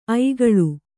♪ aigaḷu